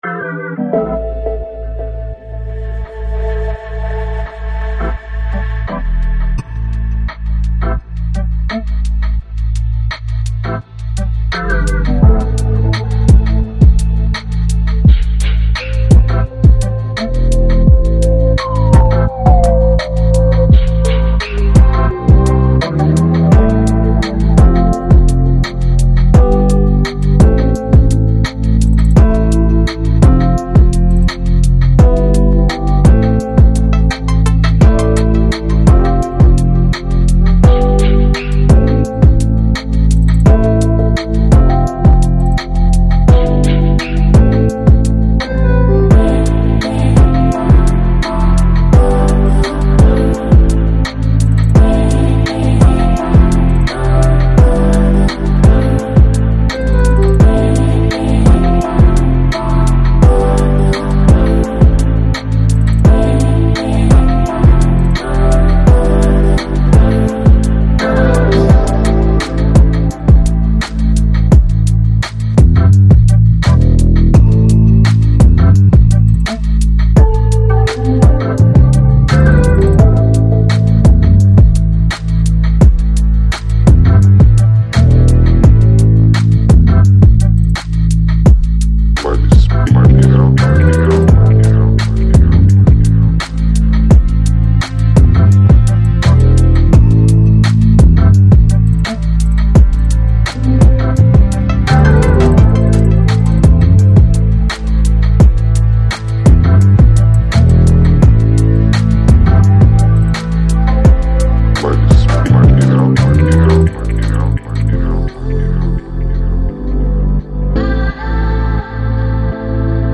Жанр: Electronic, Chill Out, Lounge, Downtempo